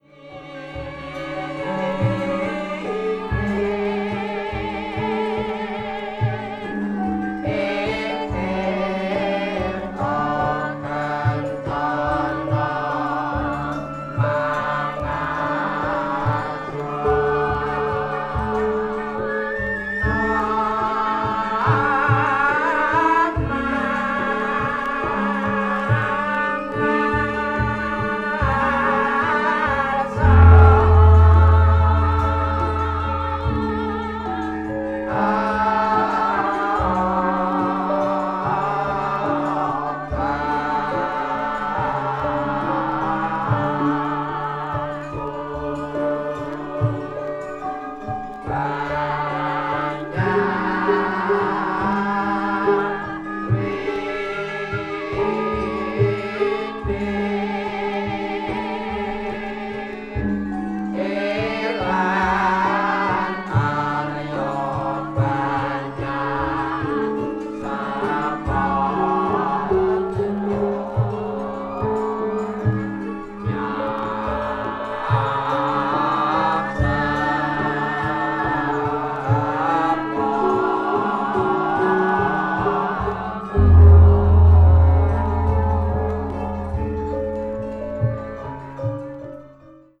media : EX-/EX-(薄いスリキズによるわずかなチリノイズが入る箇所あり)